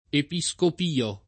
vai all'elenco alfabetico delle voci ingrandisci il carattere 100% rimpicciolisci il carattere stampa invia tramite posta elettronica codividi su Facebook Episcopio [ epi S k 0 p L o o epi S kop & o ] top. (Camp.)